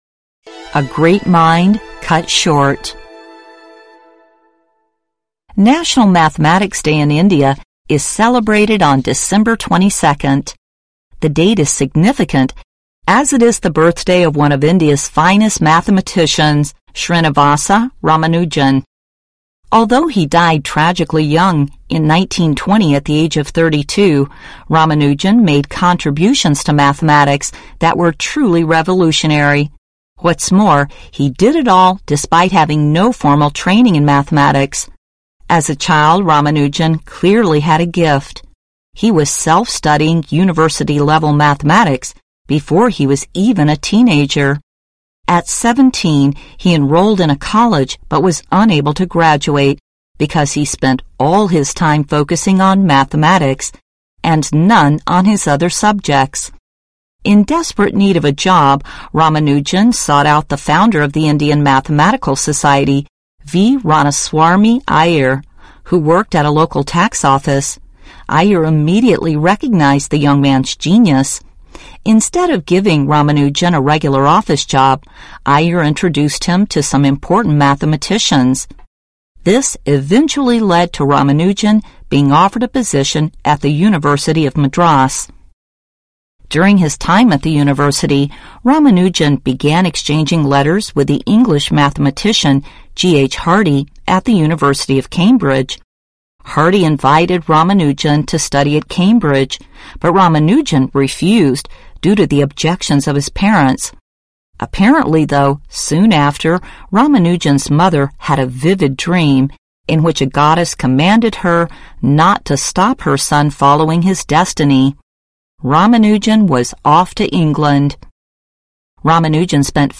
★Jared is trying to agree on a price with a customer, Jane.